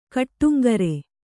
♪ kaṭṭuŋgare